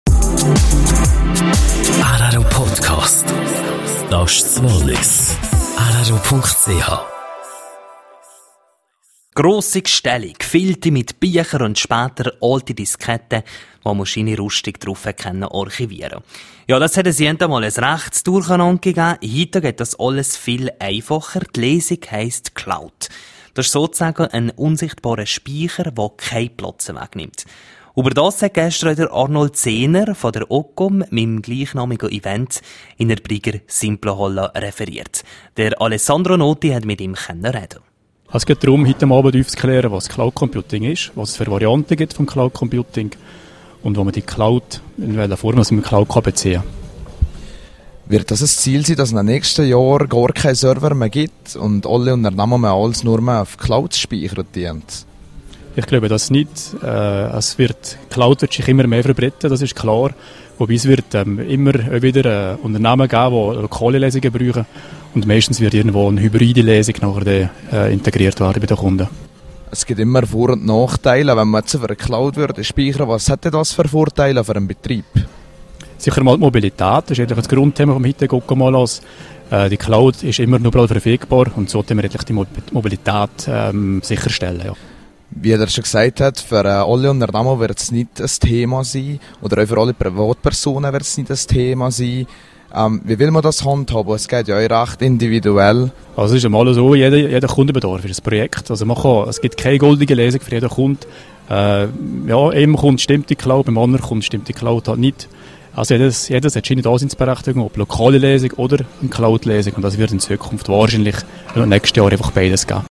Ocom-Event in der Simplonhalle. Interview